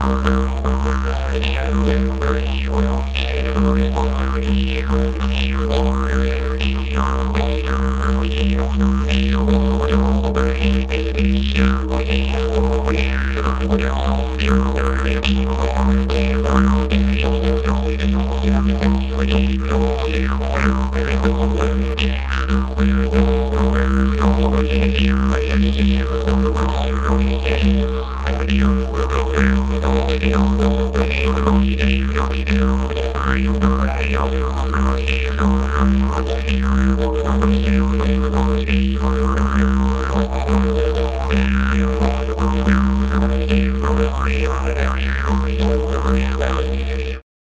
didgeridoo_.mp3